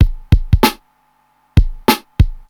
96 Bpm Drum Groove A# Key.wav
Free breakbeat - kick tuned to the A# note. Loudest frequency: 1116Hz
96-bpm-drum-groove-a-sharp-key-R4d.ogg